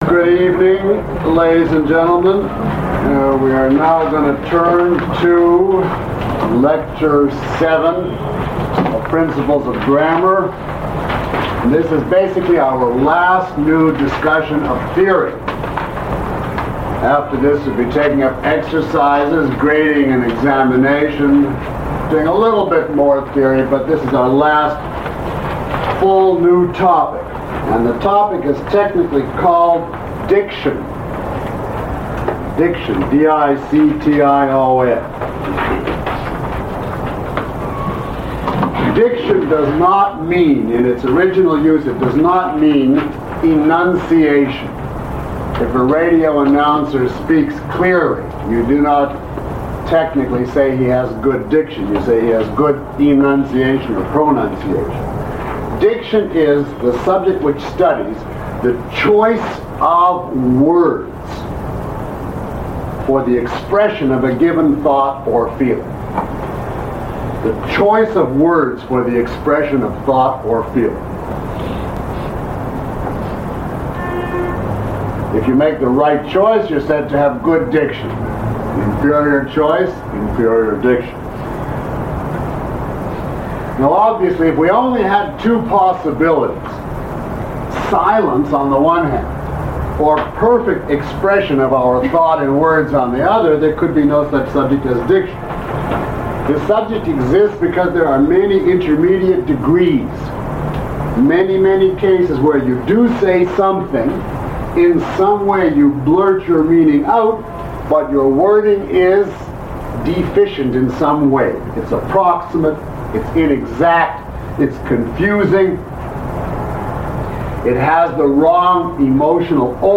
Lecture 07 - Principles of Grammar.mp3